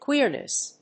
音節queer･ness発音記号・読み方kwɪ́ərnəs